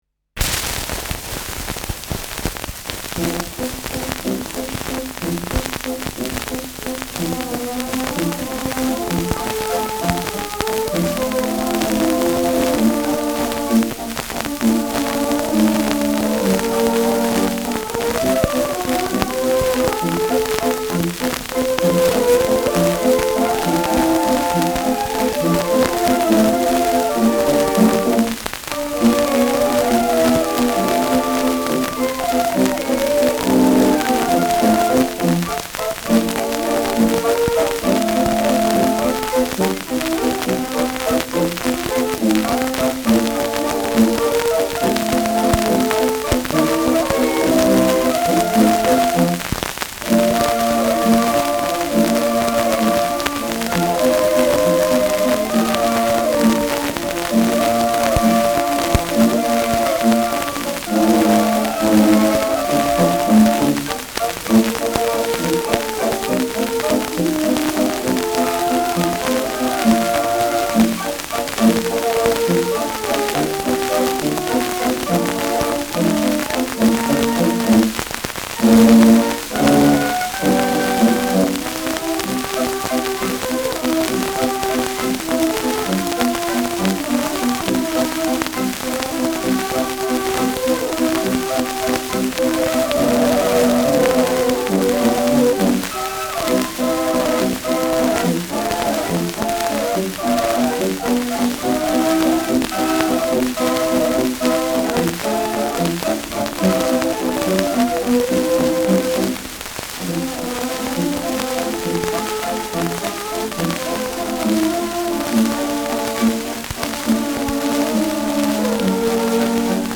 Schellackplatte
Tonrille: starker Berieb : durchgängig stark zerkratzt
[unbekanntes Ensemble] (Interpretation)